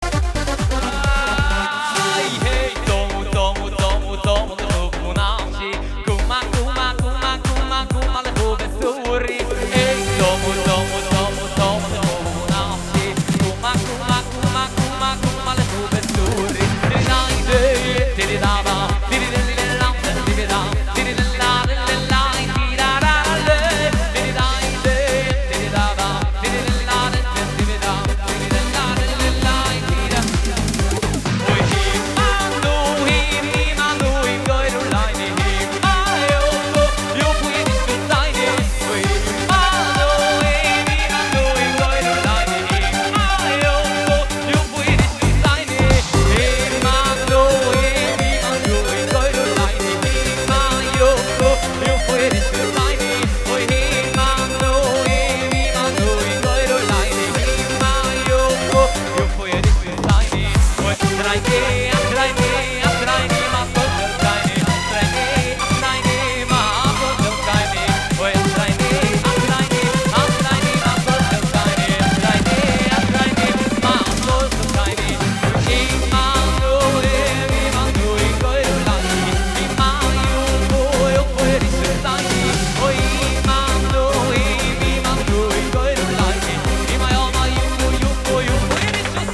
מתוך חתונה